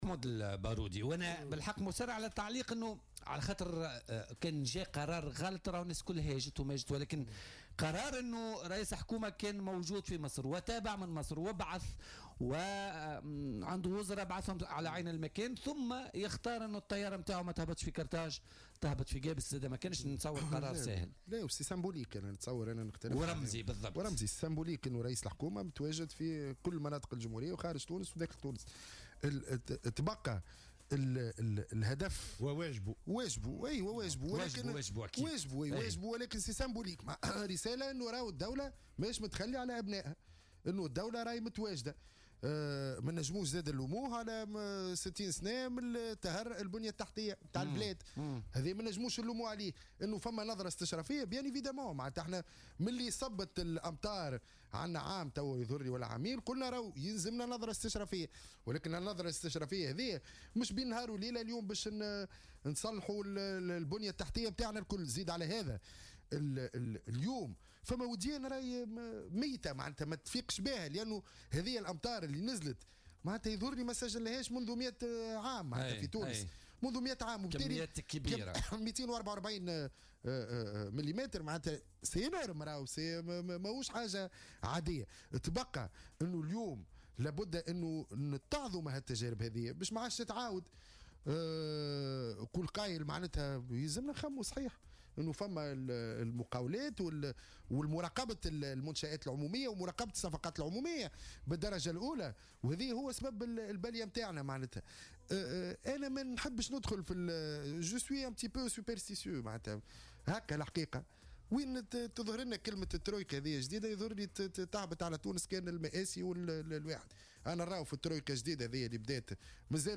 أكد الناشط السياسي محمود البارودي ضيف بولتيكا اليوم الإثنين 13 نوفمبر 2017 أن الترويكا الجديدة المتكونة من نداء نهضة و اتحاد وطني حر ستدمر ما تبقى في تونس.